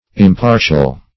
Impartial \Im*par"tial\, a. [Pref. im- not + partial: cf. F.
impartial.mp3